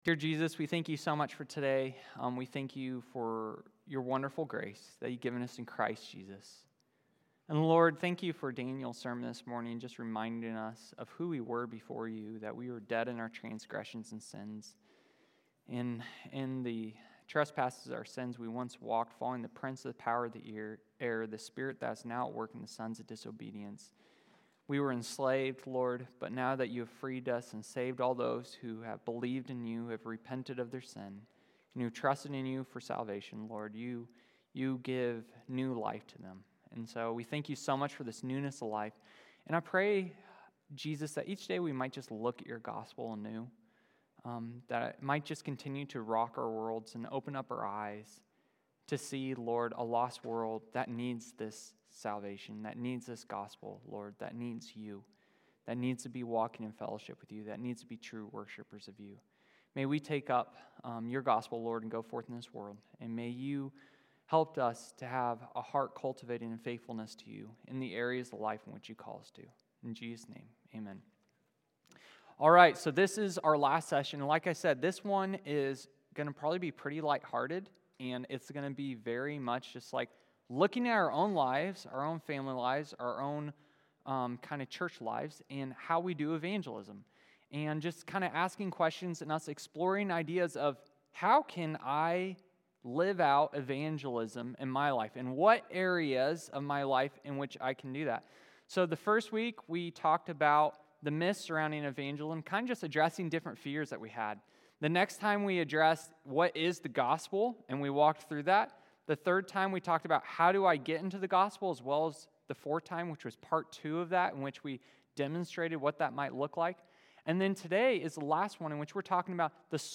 Adult Sunday School 5/25/25